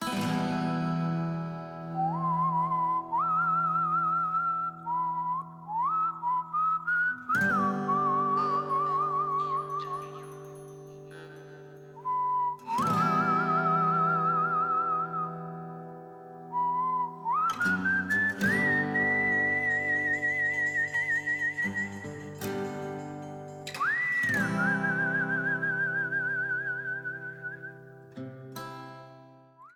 A yellow streamer theme
Ripped from the game
clipped to 30 seconds and applied fade-out